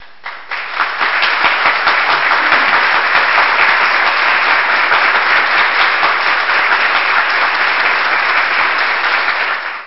도랑물 소리 &